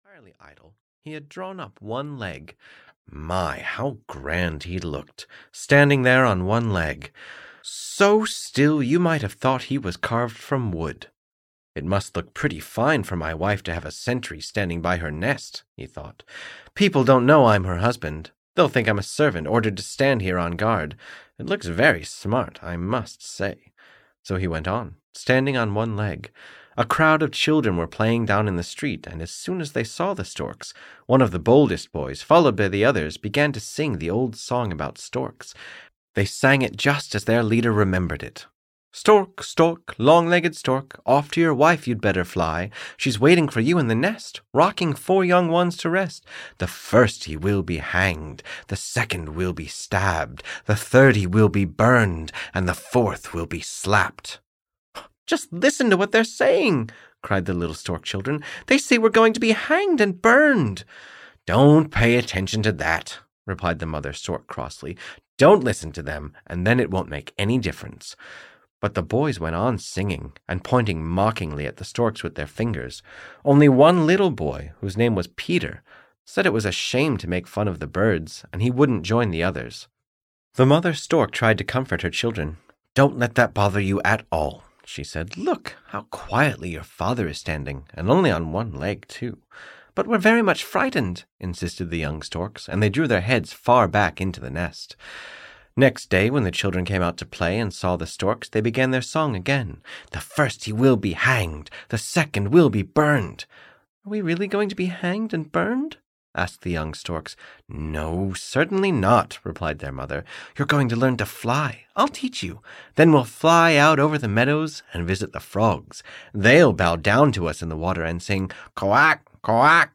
The Storks (EN) audiokniha
Ukázka z knihy